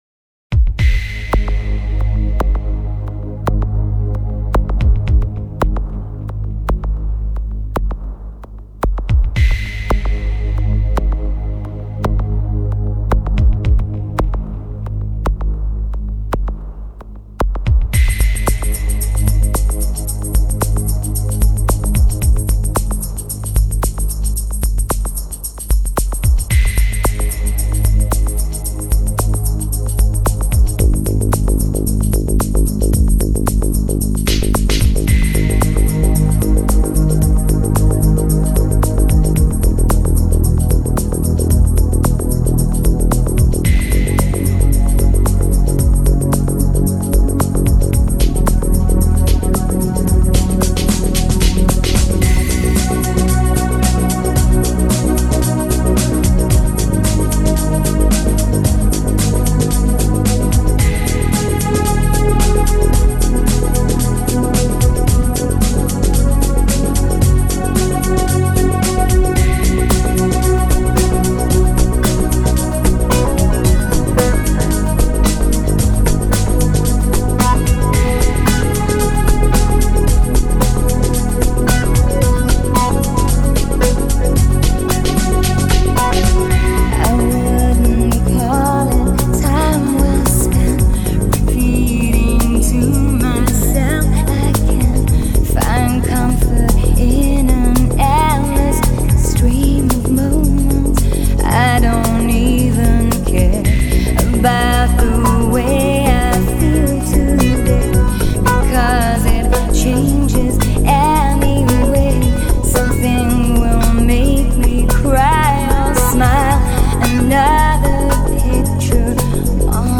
Транс Trance Транс музыка